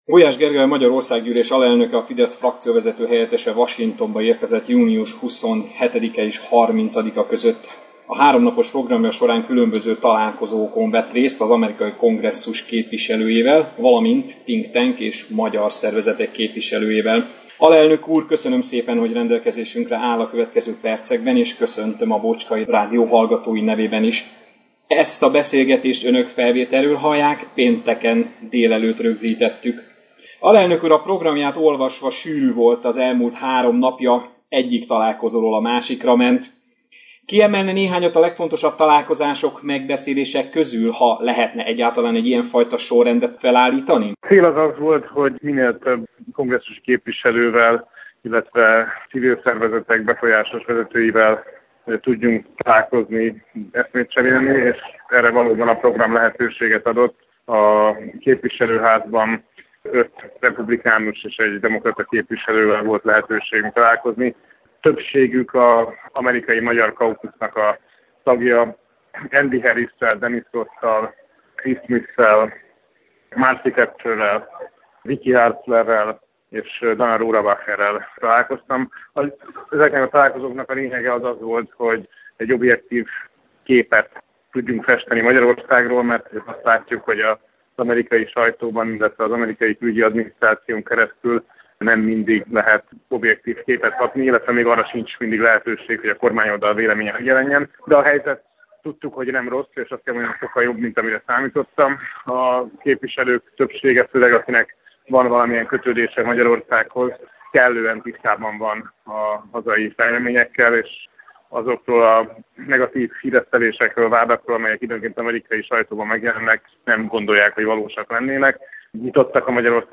Kérésünkre az alelnök úr pénteken délelőtt egy rövid telefonos interjúban készségesen válaszolt az általunk feltett kérdéseinkre. Az alábbi linken a péntek délelőtt Gulyás Gergellyel készült interjút teljes terjedelmében meglehet hallgatni.